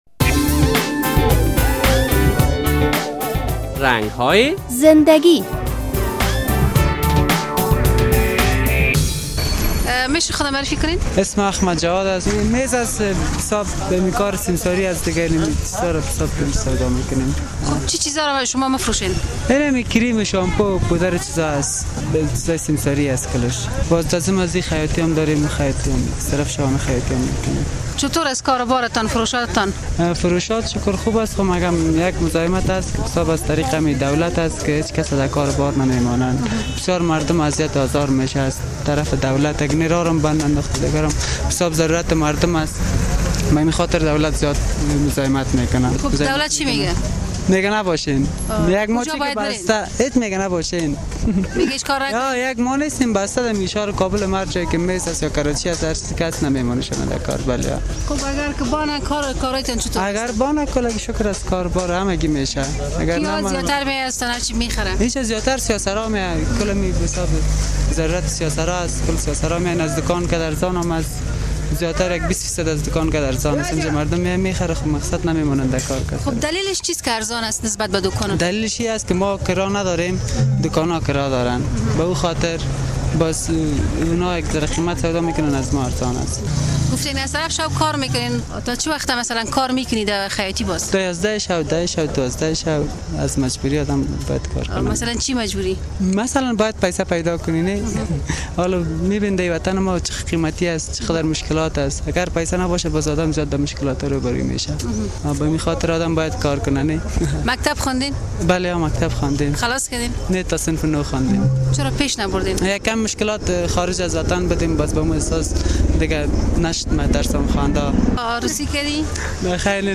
اکثراً دست فروشان در کنار جاده های شهر کابل اجناس را ارزانتر از دوکان ها بفروش می رسانند و بازار آنها نیز گرم است ولی مسوولین بخاطر اینکه آنها باعث ازدهام ترافیکی می شوند مانع این کار می شوند. یک تن از سیمساران شهر کابل اینگونه قصه می کند: